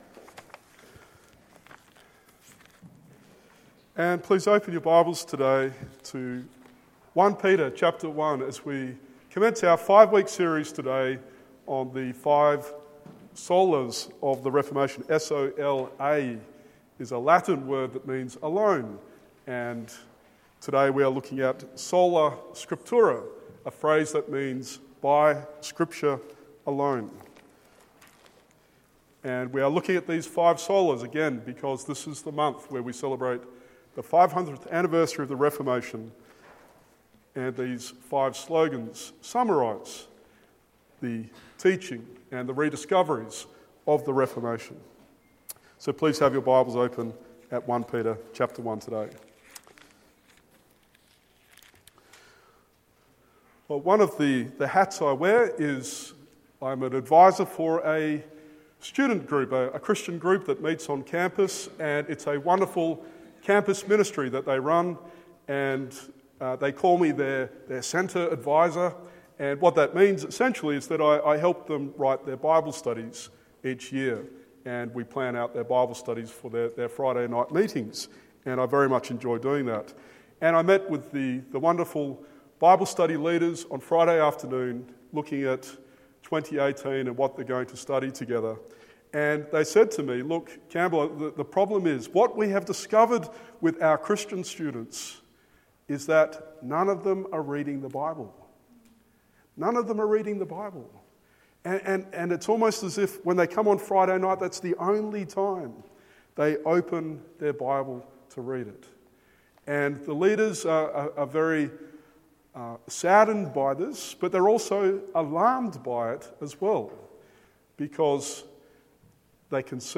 1 Peter 1:22-25 Sermon